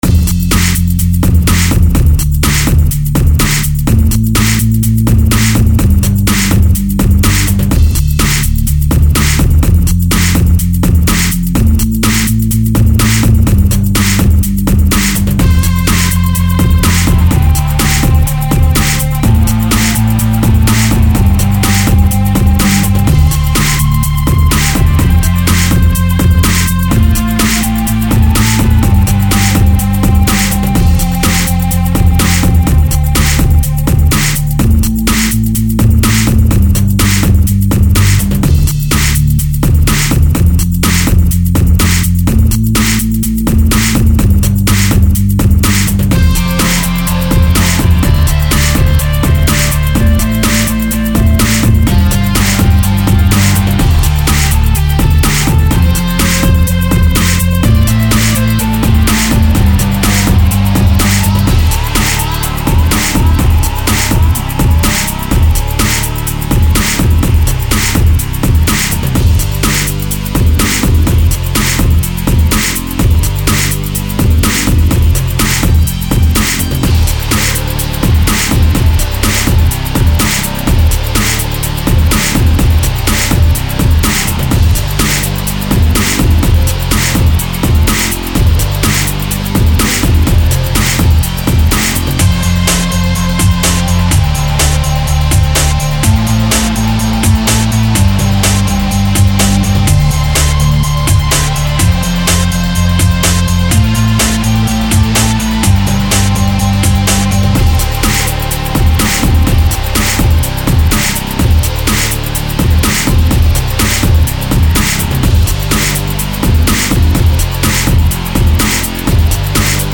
electronic
no sample mix
Pounding new track